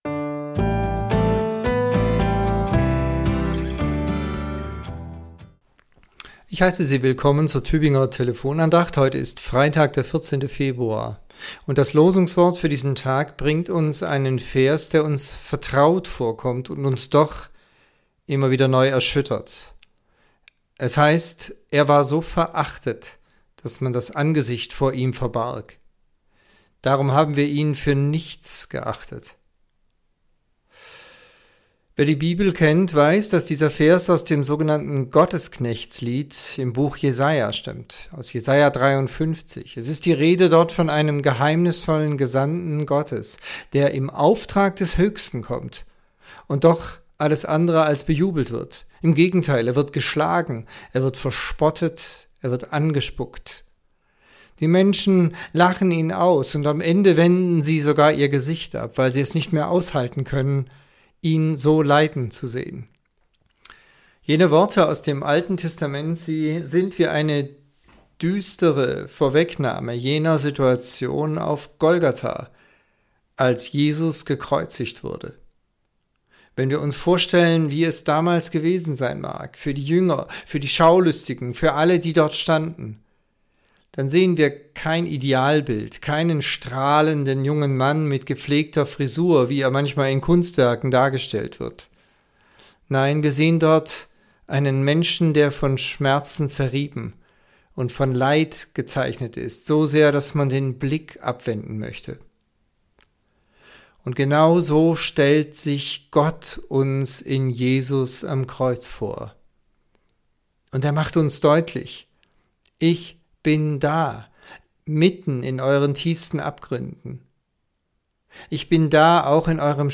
Andacht zur Tageslosung